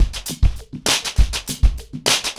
PrintOuts-100BPM.3.wav